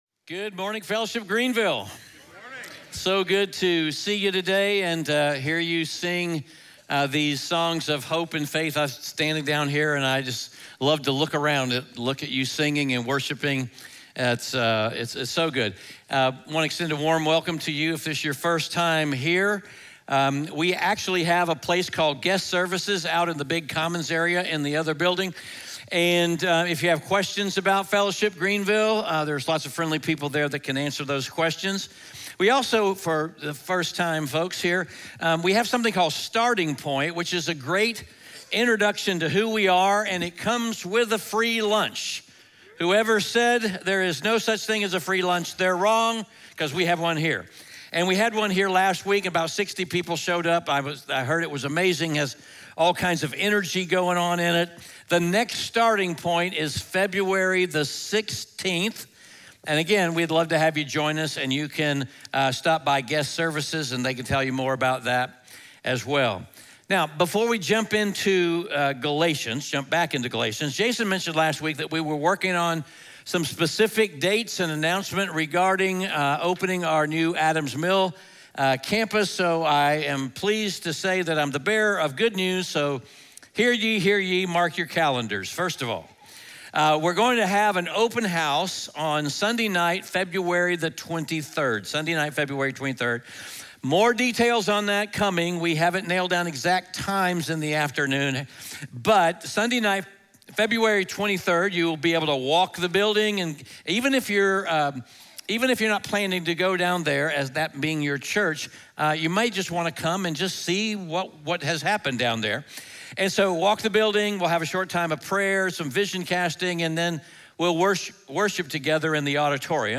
Galatians 5:1-9 Audio Sermon Notes (PDF) Ask a Question SERMON SUMMARY The Gospel tells us that our relationship with God depends 100% on what Jesus has done for us, not on what we do for Him.